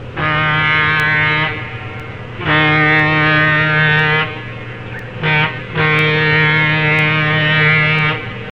Locomotive Approaching Public Grade Crossing Whistle .mp3 {long-long-short-long}
Locomotive_Approaching_Public_Grade_Crossing_Whistle.mp3